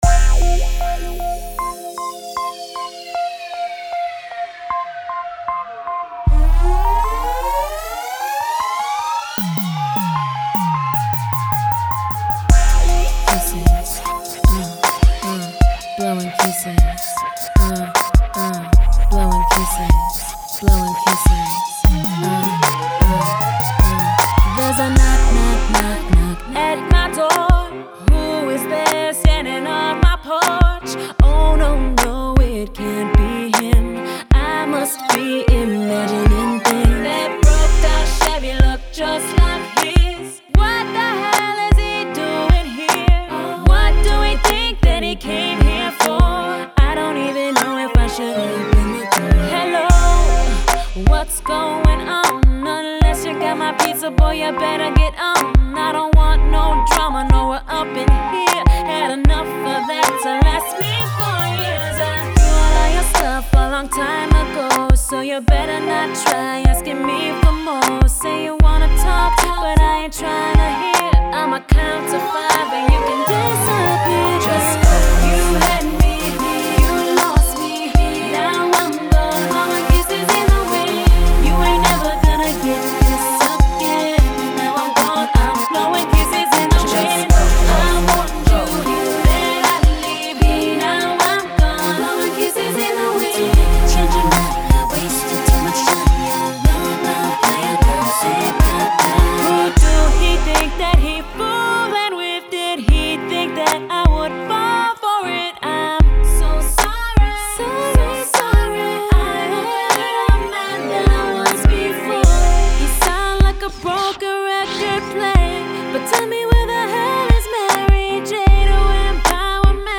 rnb track